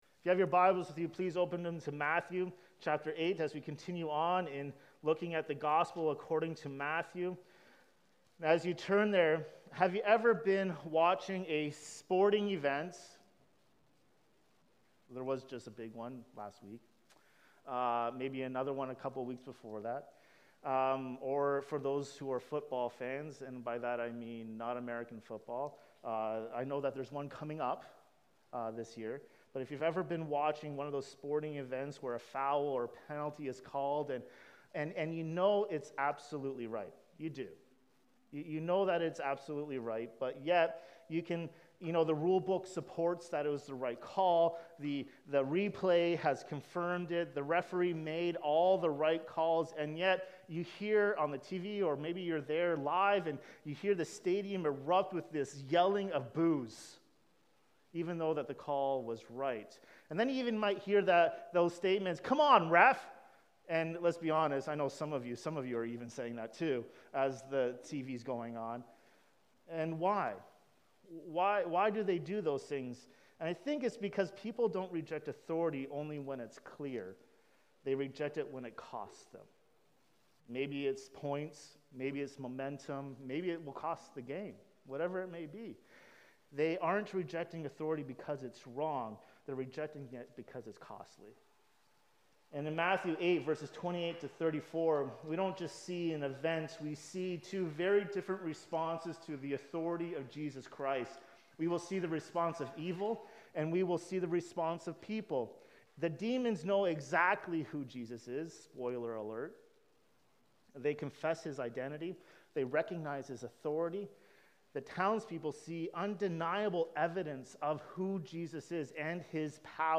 Jesus Drives Out Demons | Matthew 8:28–34 Sermon | A King and A Kingdom